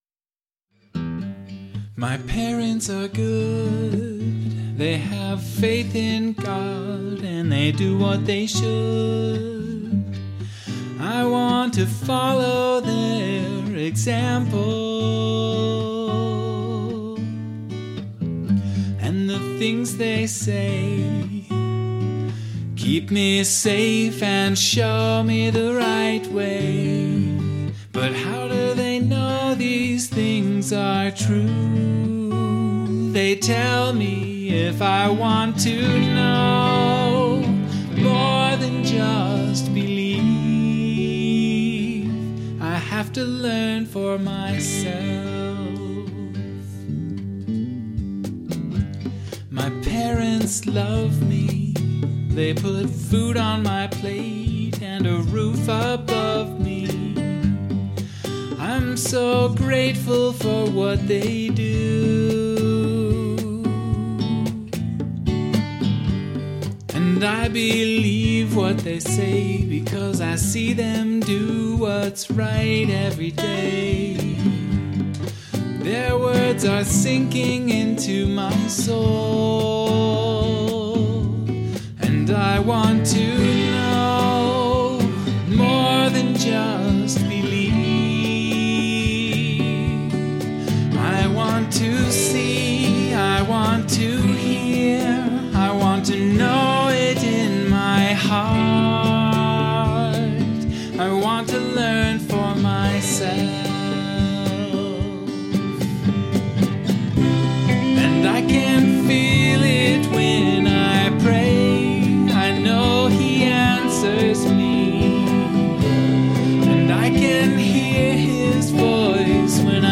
The short version is that it is a contemporary Christian song about growing up in a believing family but finding my own anchor of faith.
This was a solo recording done in my basement studio using all Free Software: Linux Mint 19.3, Ardour 5.12.0, Hydrogen drum machine, Guitarix virtual amplifier and effects, Dragonfly Reverb 3, Calf Studio Gear, setBfree virtual tonewheel organ, Linux Studio Plugins, and AVL Drumkits.
The instrumentation was fairly simple. I played my Takamine G-330 acoustic guitar, Ibanez AM53 semi-hollow body, and Austin Bazaar bass. The tonewheel organ, piano, and percussion sounds were all midi instruments.
The backing vocals are a 3-part harmony layered 4 voices deep.